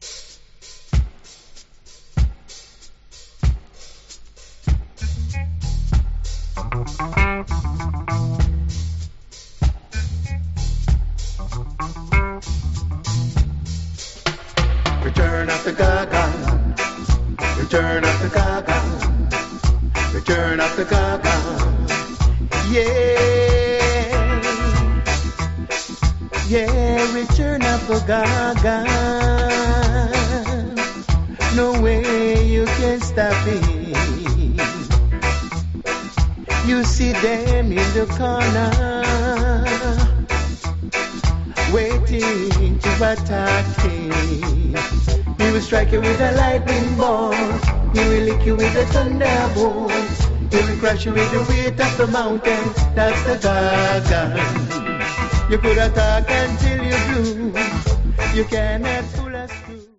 Genre: Dub Reggae.